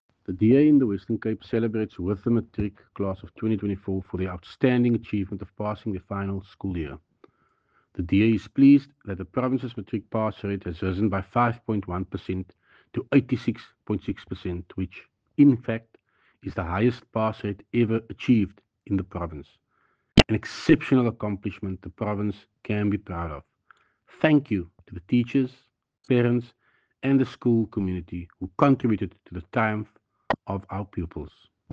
Issued by Tertuis Simmers – DA Western Cape Leader
soundbite by Tertuis Simmers